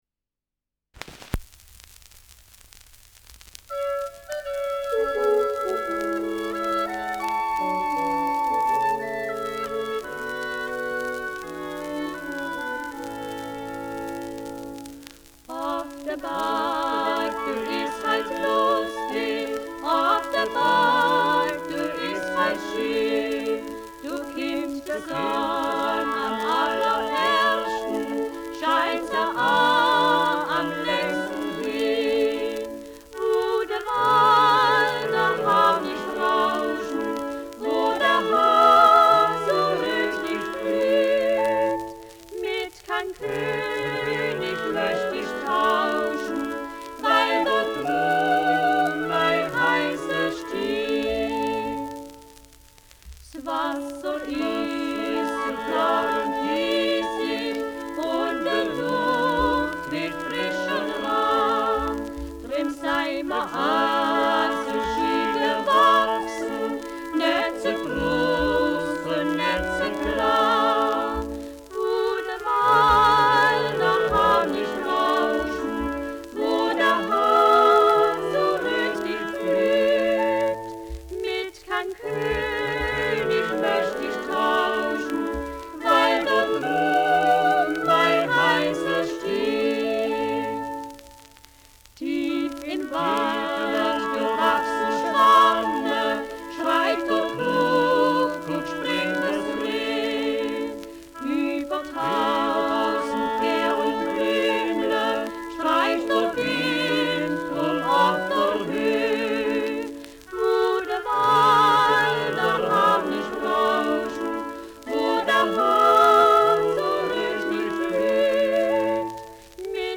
Schellackplatte
Stärkeres Grundknistern : Gelegentlich leichtes Knacken
Folkloristisches Ensemble* FVS-00015